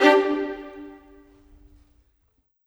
Rock-Pop 07 Violins 03.wav